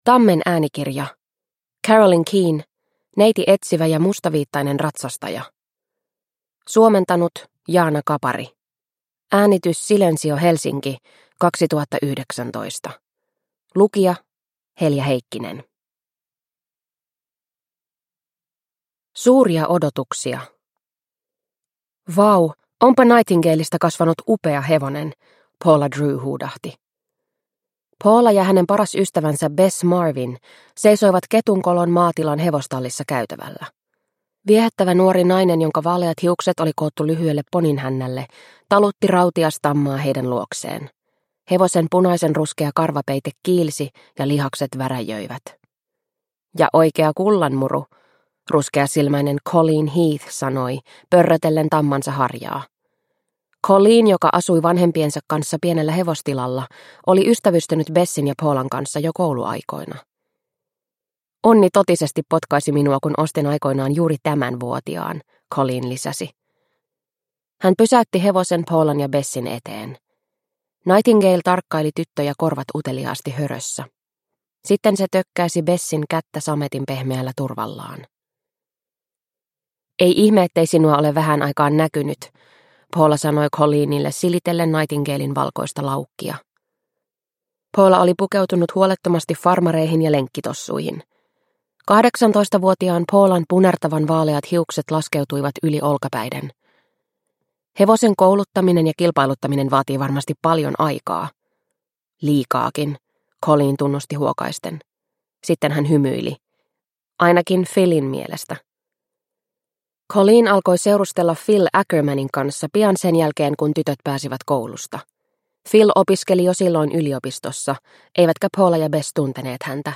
Neiti Etsivä ja mustaviittainen ratsastaja – Ljudbok – Laddas ner